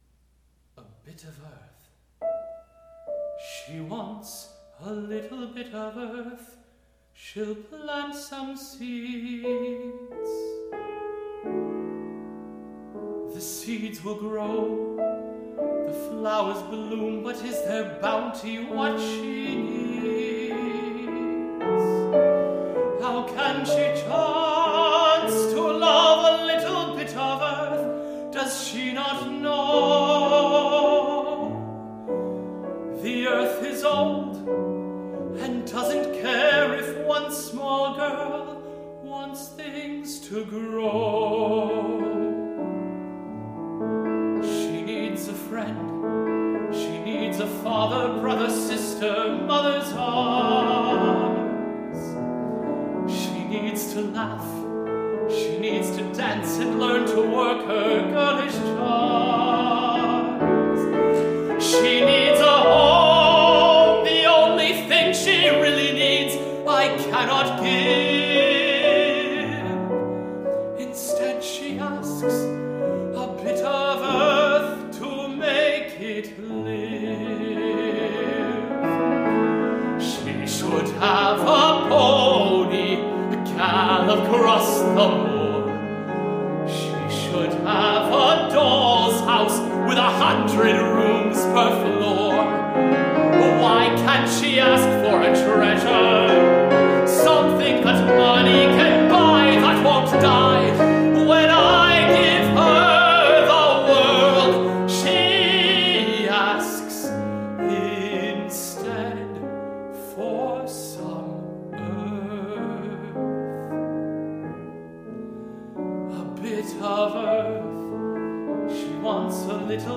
live demo